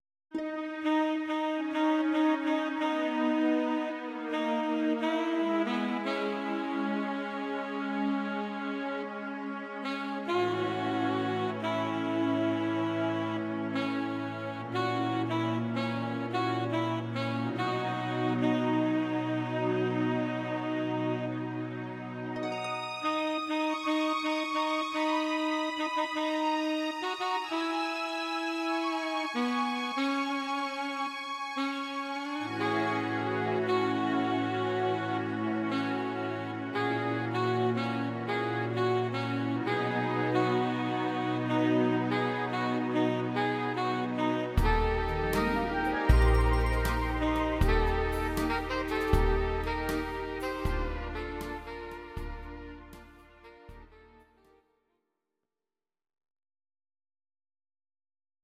Audio Recordings based on Midi-files
Pop, Musical/Film/TV, 1960s